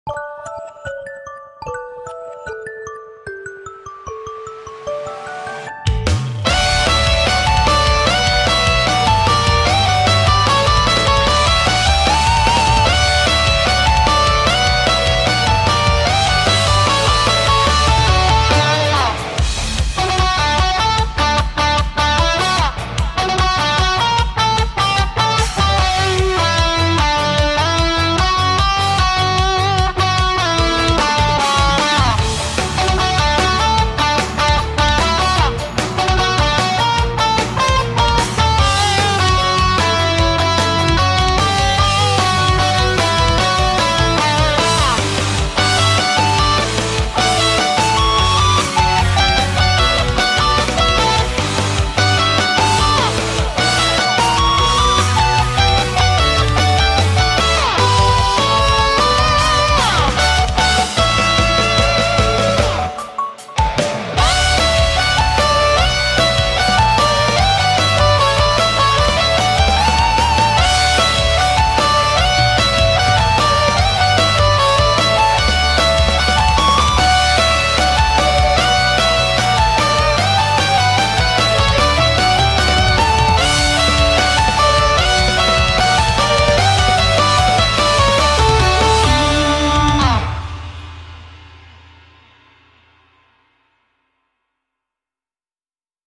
• Качество: высокое
Анимешная музыка для будильника